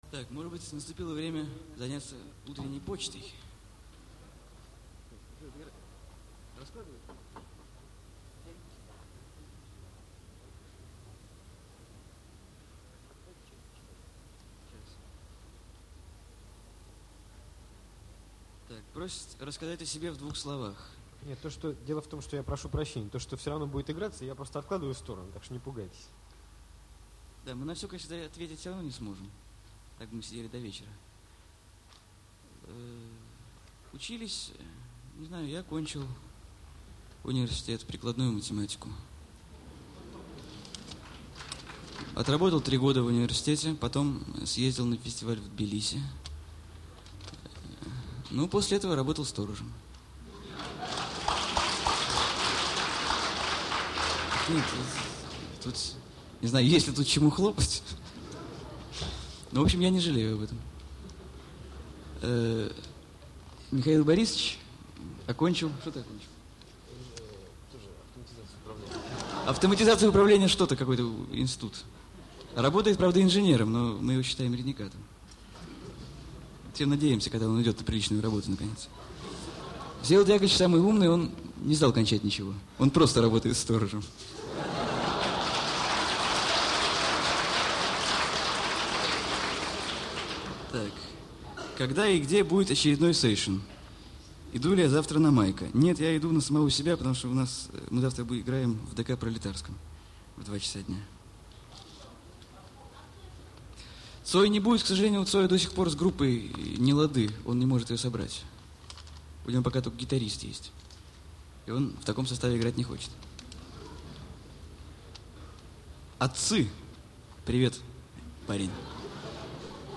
Концерт в Военмехе